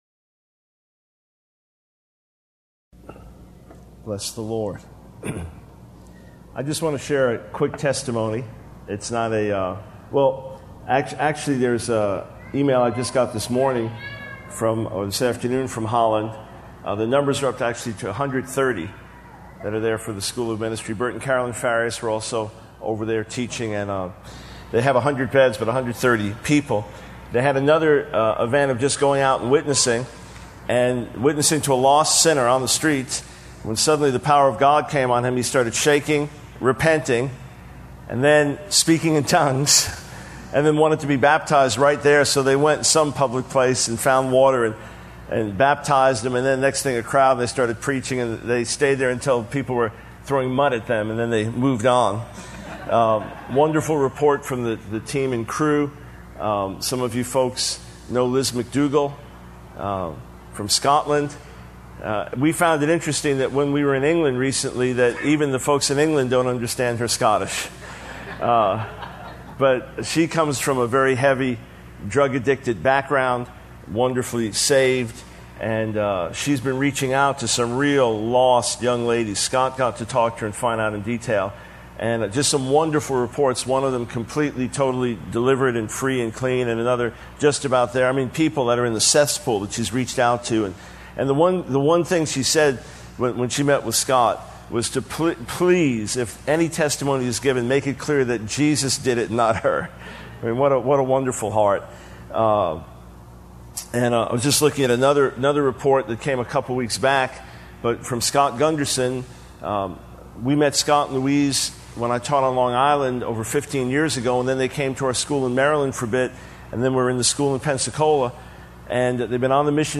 In this sermon, the speaker begins by sharing a personal story about his wife's experience with data loss on her computer. He then transitions to discussing the importance of trusting in God's faithfulness and not being swayed by circumstances or the voice of the devil. He encourages the audience to have faith and trust in God's promises, using examples from the Bible, such as Moses and the Israelites.